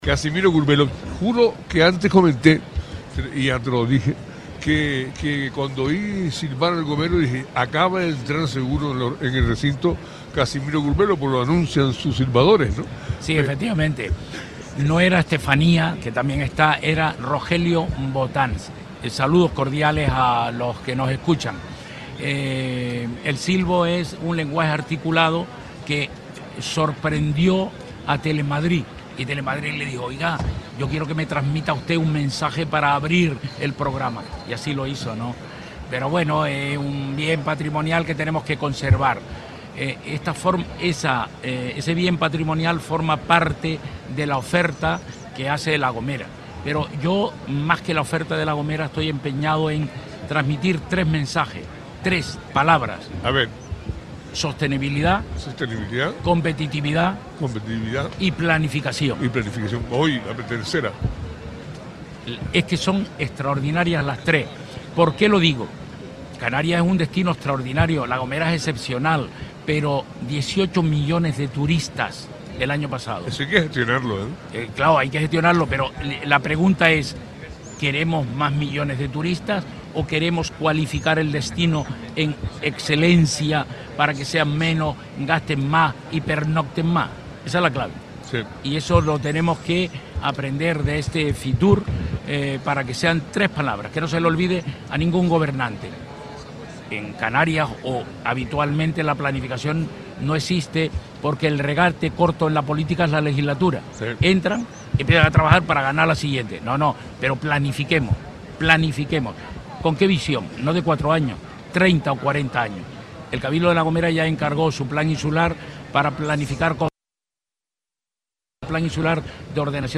Entrevistas
El presidente del Cabildo Gomero Casimiro Curbelo en el especial Fitur2025 de Radio Sintonia en Madrid explicó los detalles de la presencia de su isla en esta cita internacional del turismo donde se presentará el proyecto La Gomera 36.Un proyecto de economía circular y sostenibilidad.